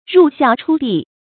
入孝出弟 注音： ㄖㄨˋ ㄒㄧㄠˋ ㄔㄨ ㄊㄧˋ 讀音讀法： 意思解釋： 見「入孝出悌」。